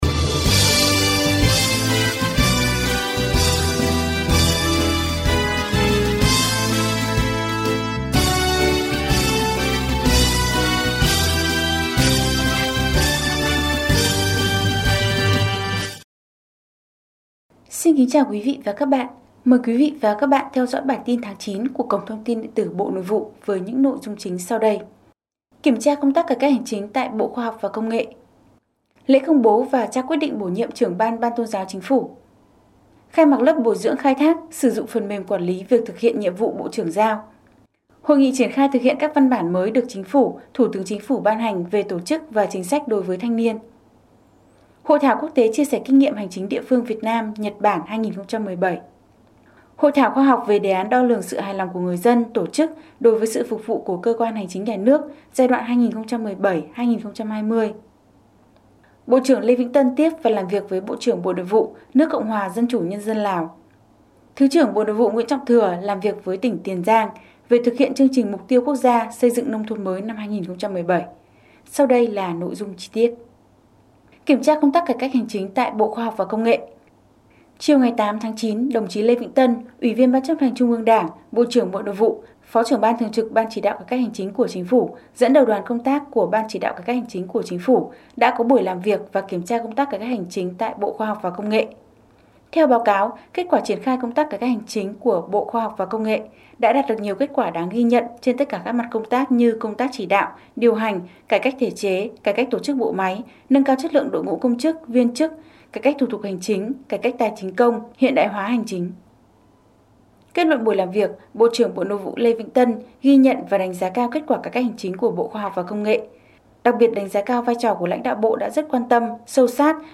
Bản tin audio ngành Nội vụ số tháng 9 năm 2017